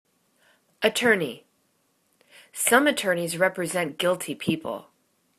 at.tor.ney     /ə'turni/    n